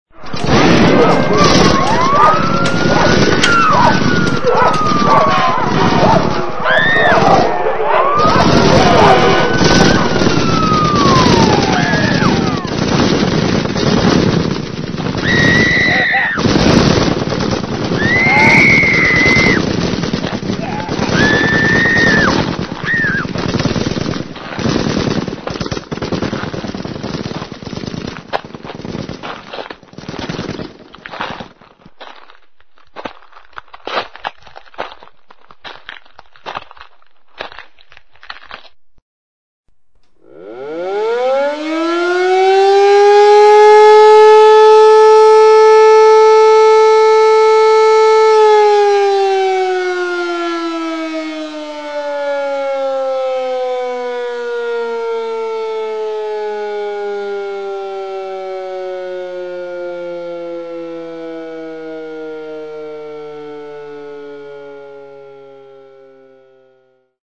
Tonos gratis para tu telefono – NUEVOS EFECTOS DE SONIDO DE AMBIENTE de GUERRA GUERRA
Ambient sound effects
guerra_guerra.mp3